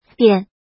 怎么读
biān